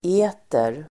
Uttal: ['e:ter]